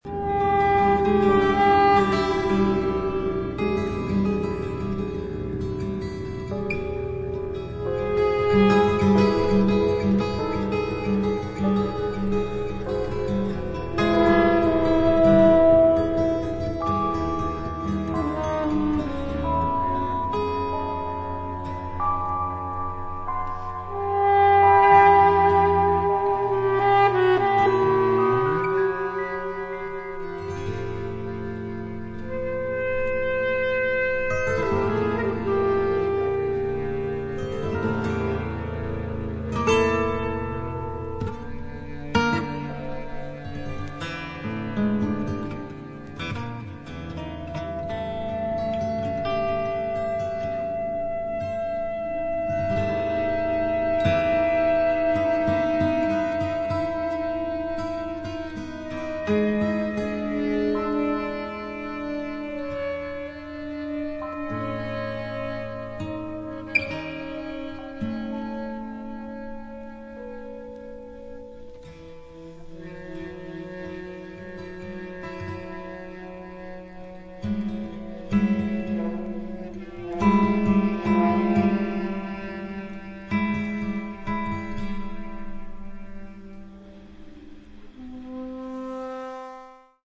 サクソフォーン、インド竹笛、ボリビア笛、鳥笛、オカリナ etc.
ギター
ベース